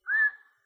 Whistle Stop.wav